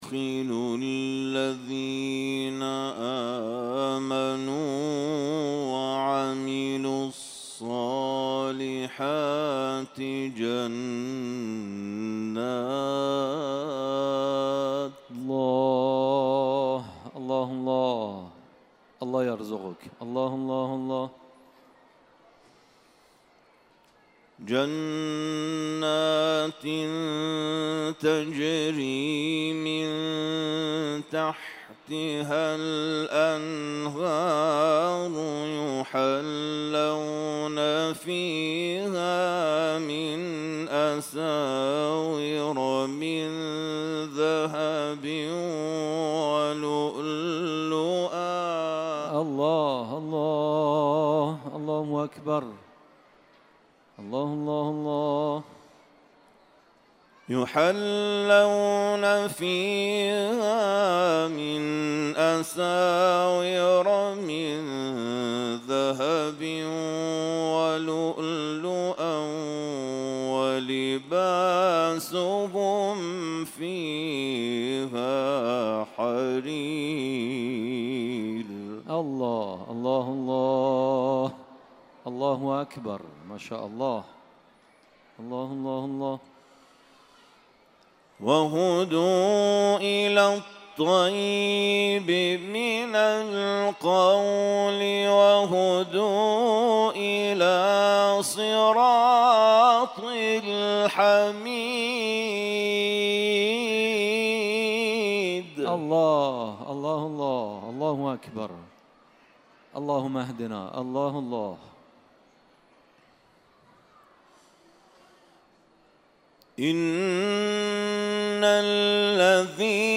محافل و مراسم قرآنی